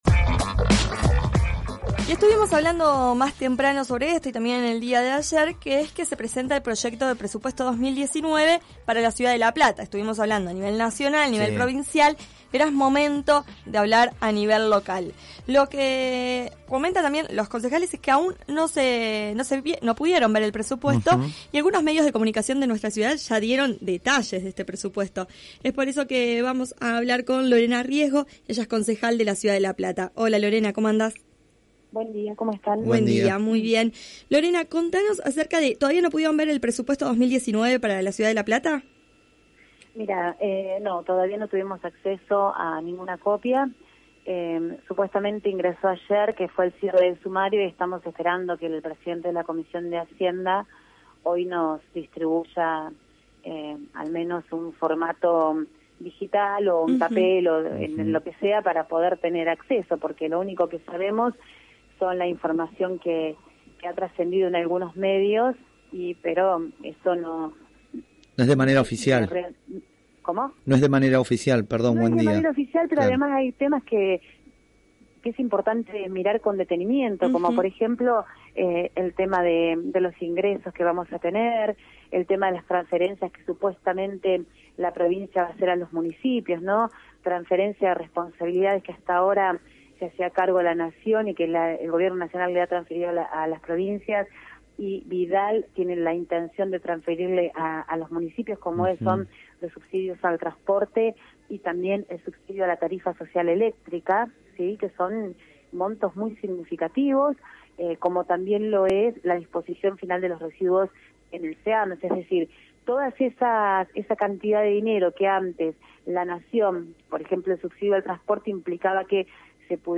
(21-11-18) Hoy en la mañana, Clase Turista habló con la concejal Lorena Riesgo, quien manifestó su preocupación sobre el Presupuesto 2019 que presentó Julio Garro para La Plata y al que aún no tuvieron acceso los concejales.